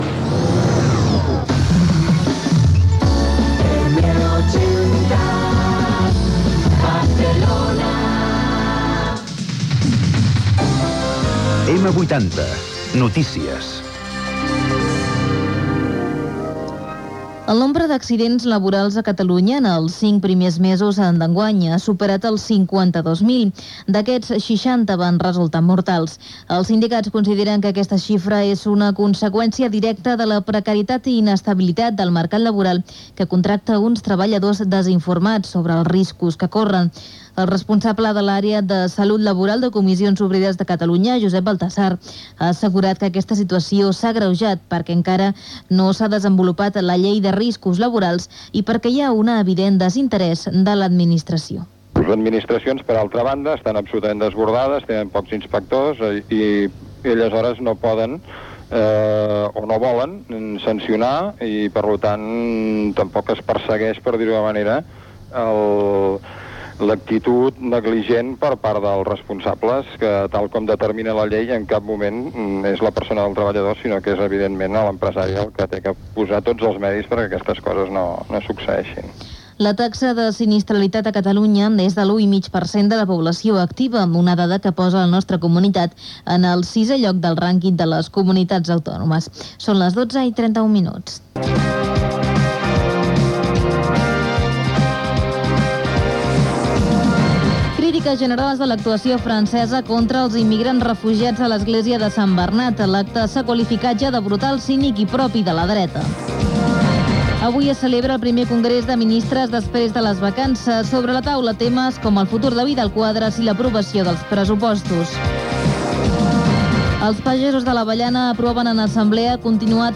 Informatiu
FM